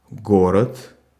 Ääntäminen
France: IPA: /vil/